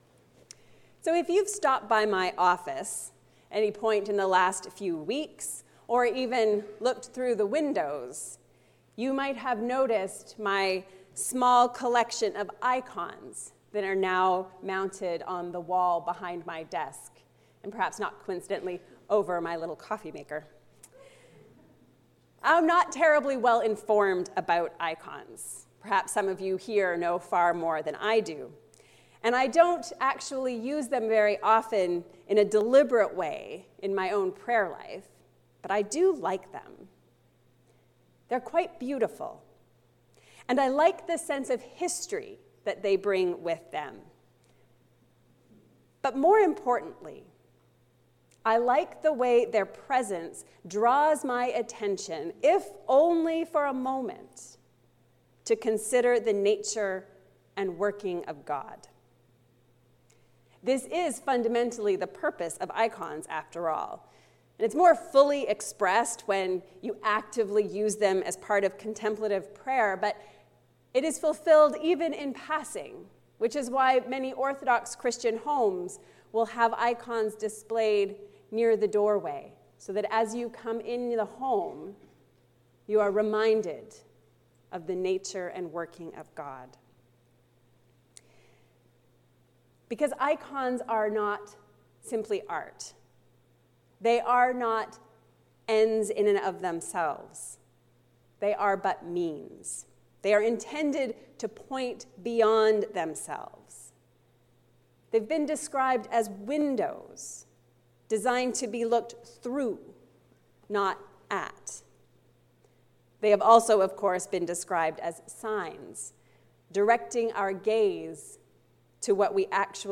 sermon-Oct-9_mono3.mp3